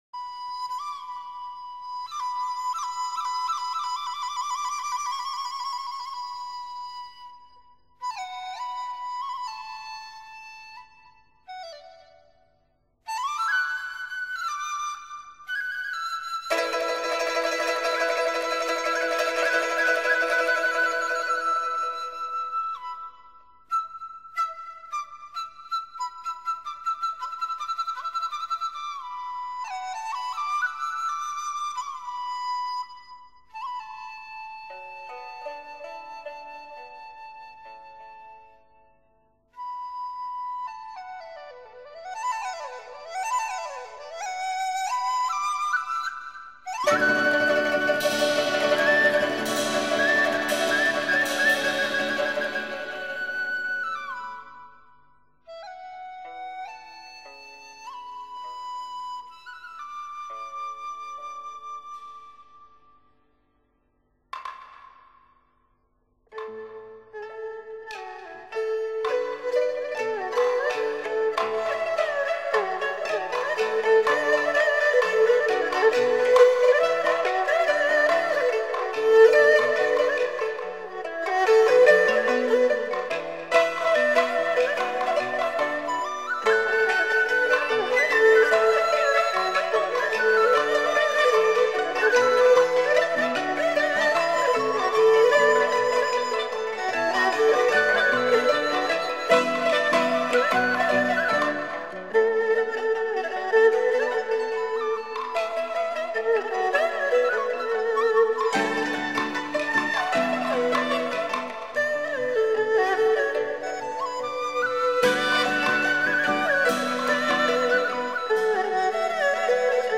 本碟汇集了近几十年来新创的中国民族音乐
只有中国乐器丝和竹的颤鸣才能纯净那无法压抑的欲望